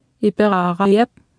Speech synthesis Martha to computer or mobile phone
Speech Synthesis Martha